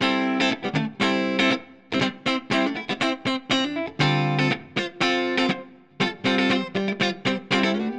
29 Guitar PT4.wav